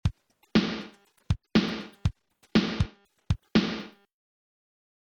SICK_ASS_HEIST_FANFARE.mp3